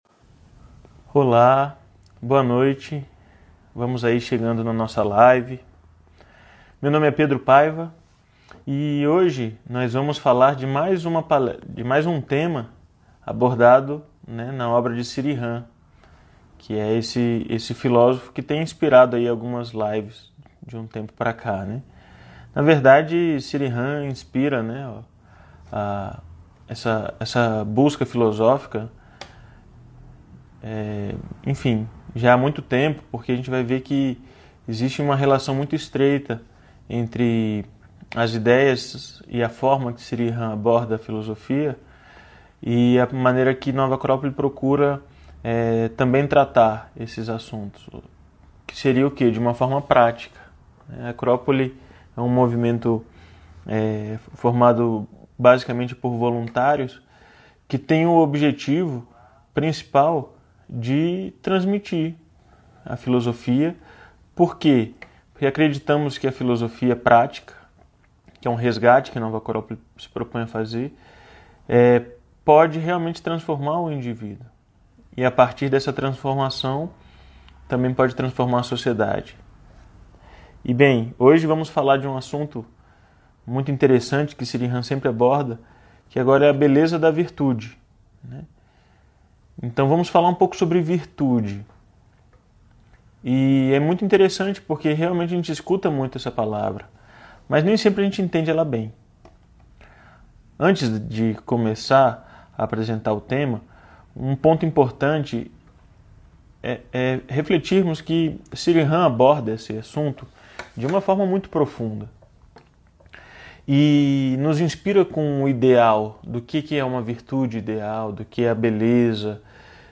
#163 -A Beleza da Virtude - live baseada na obra de Sri Ram